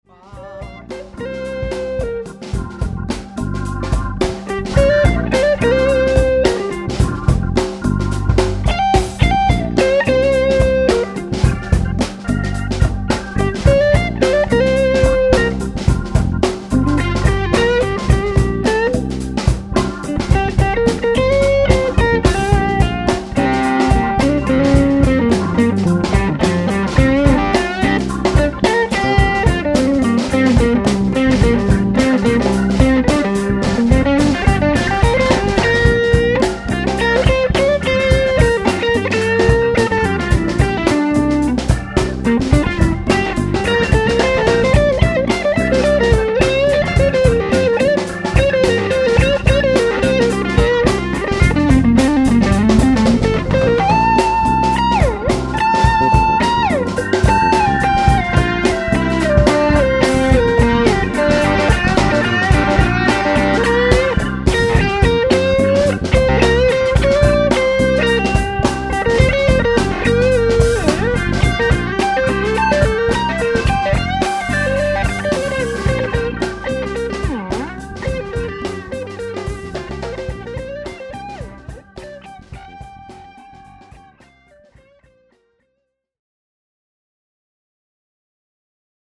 chitarra
voce e congas